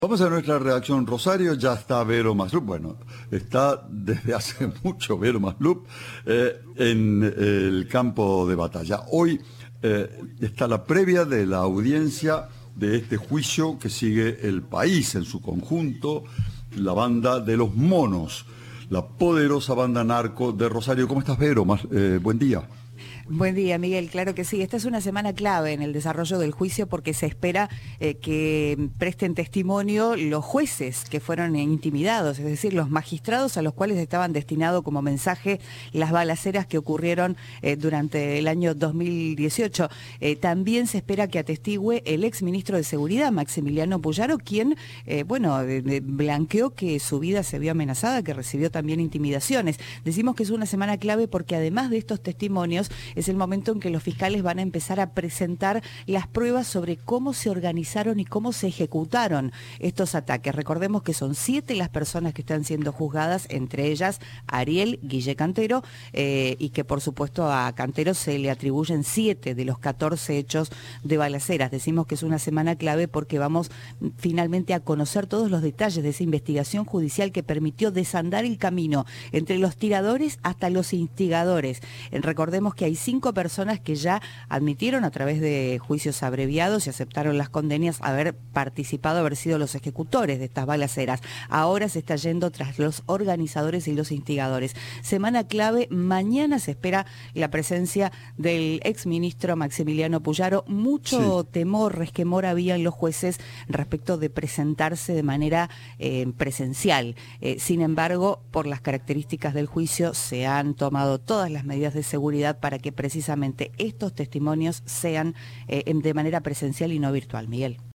Informe de